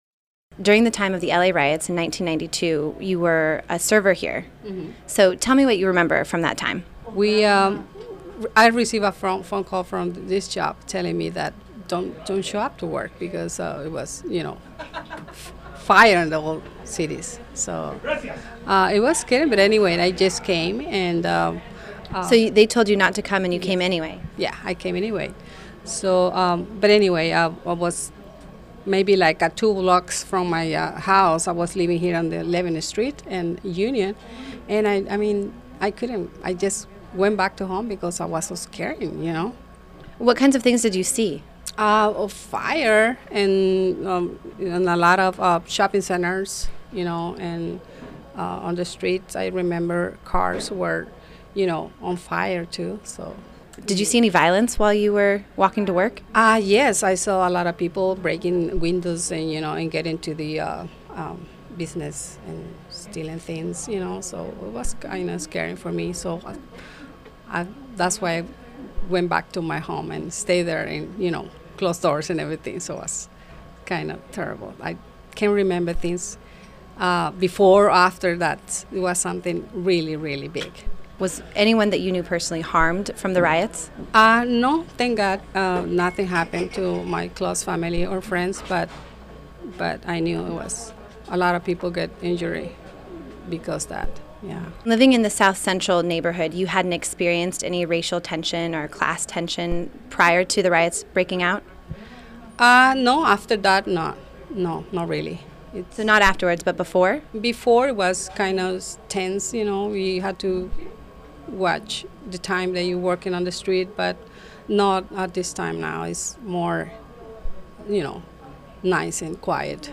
La_Riot_Host_Interview.mp3